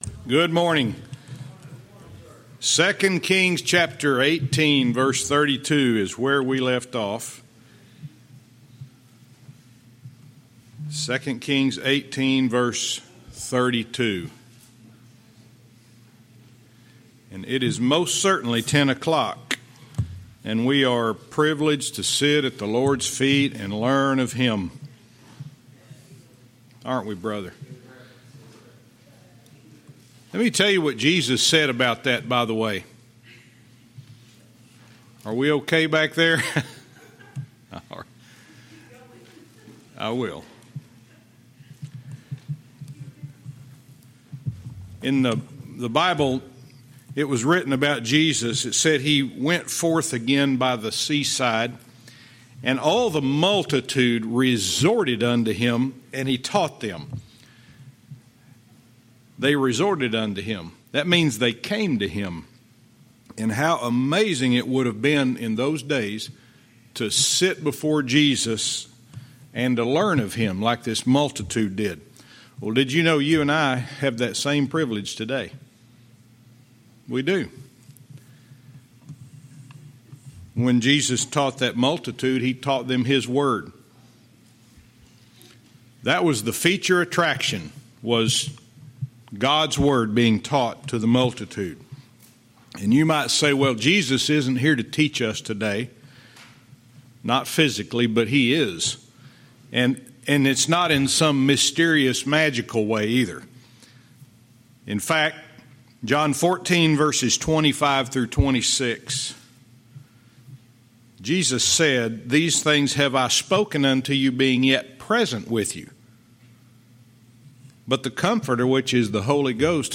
Verse by verse teaching - 2 Kings 18:32 Part 5